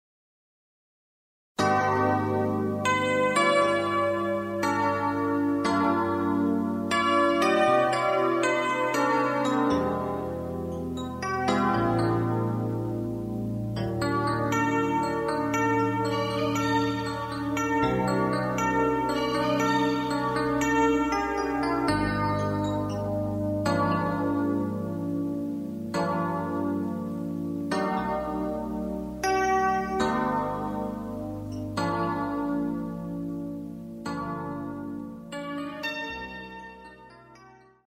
Muestras de las pistas